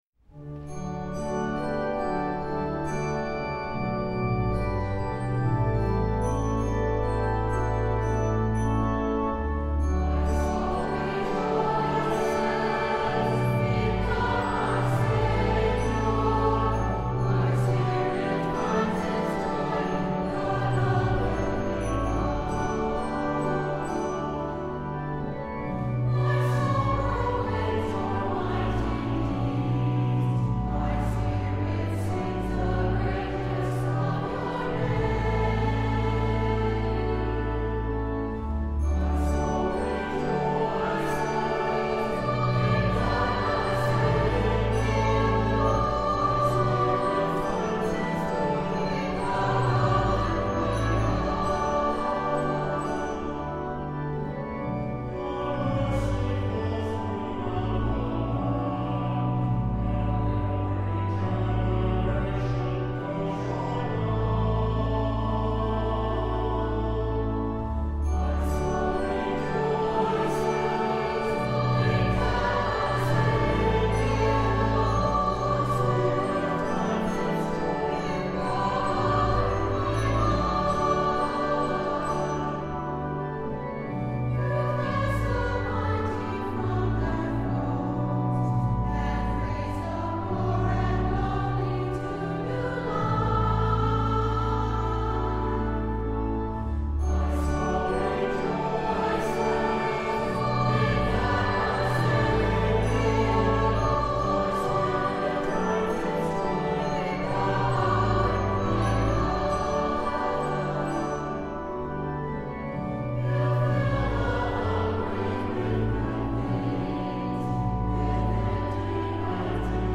150th Anniversary My Soul Rejoices St. Mary Choir